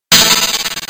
cpu_bonus_heal.ogg